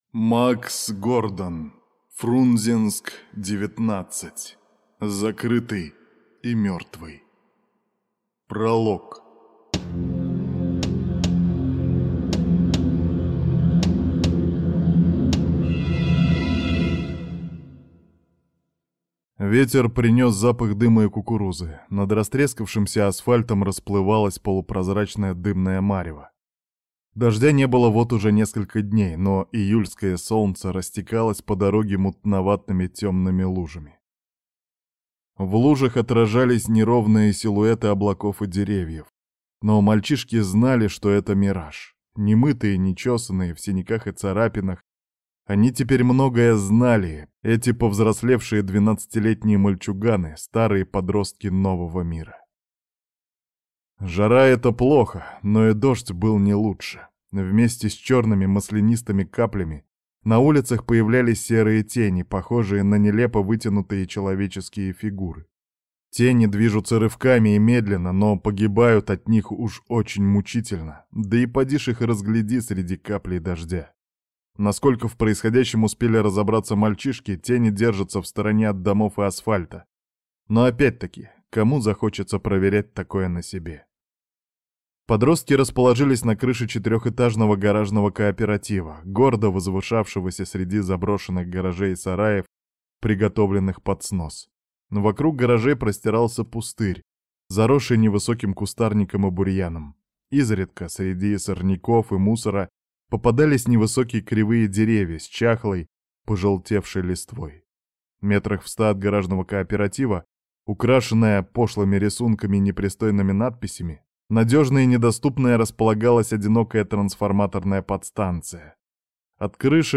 Аудиокнига Фрунзенск-19. Закрытый и мертвый | Библиотека аудиокниг